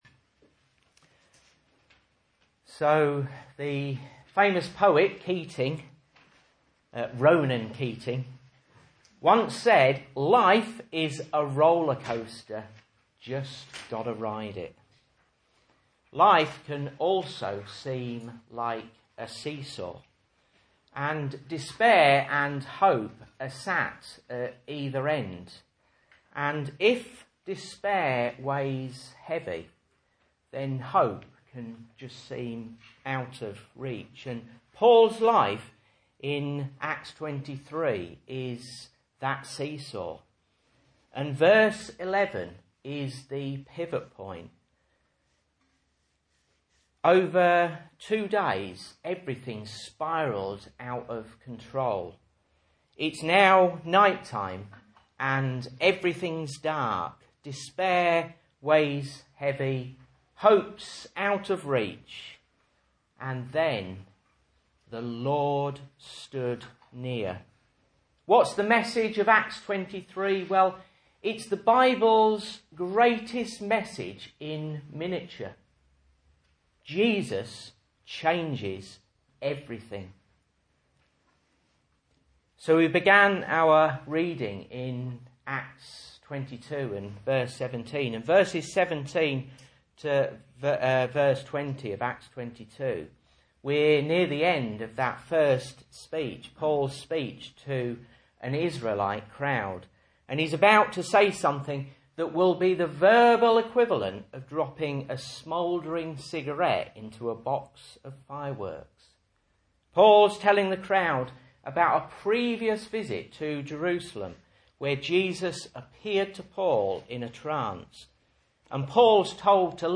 Message Scripture: Acts 22:17-23:35